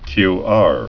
(kyär)